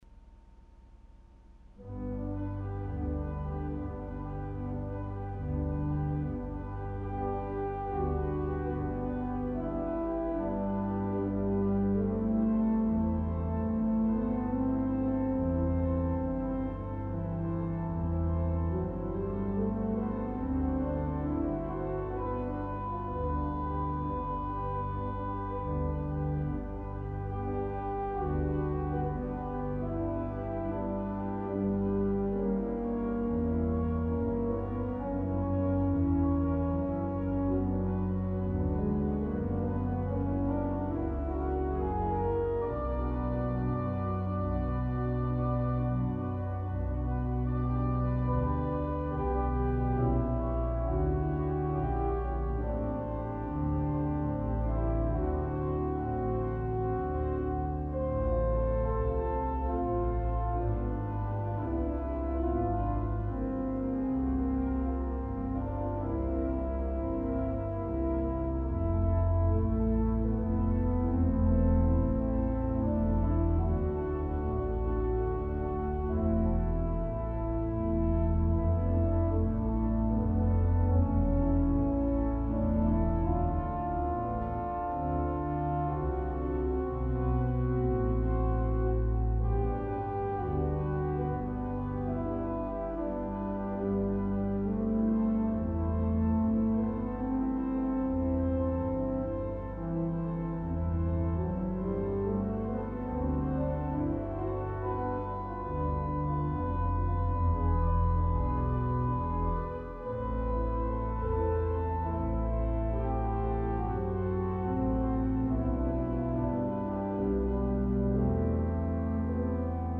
orgue Saints-Anges-Gardiens, Lachine, Québec.